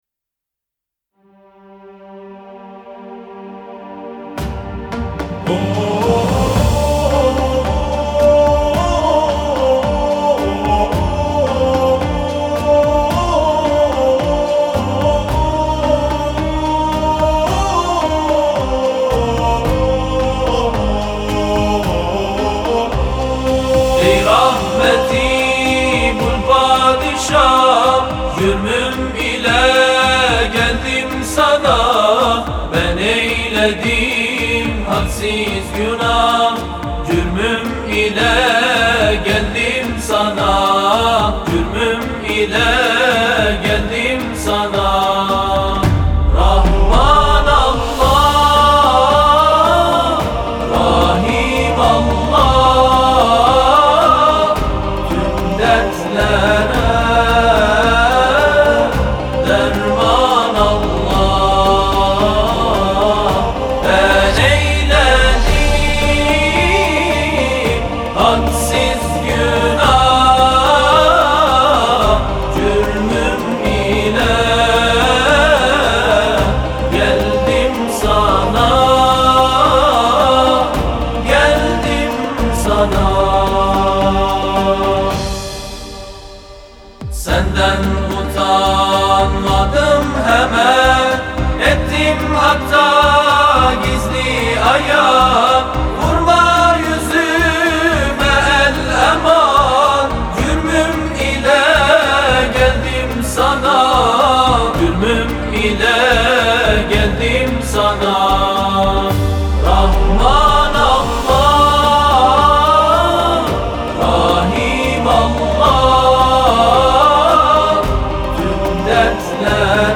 گروه تواشیح